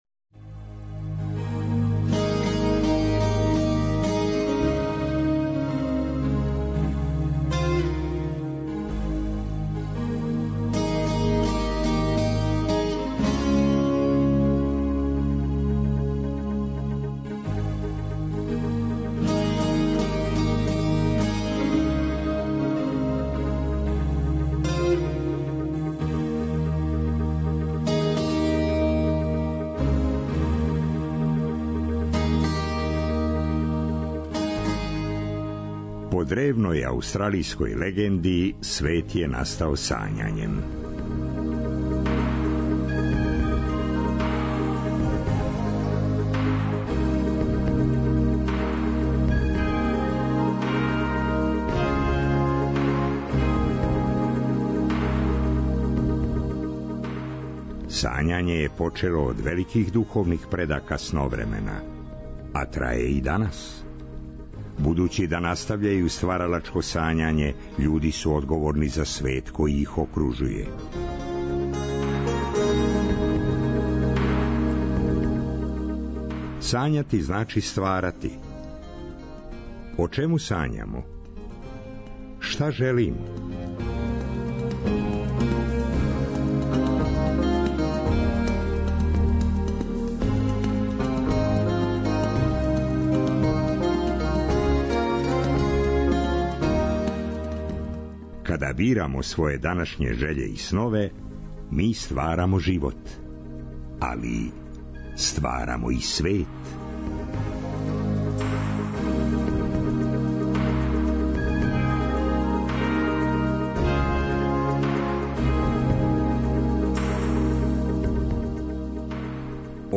Пролећна ноћ уз музику и приче са разних страна...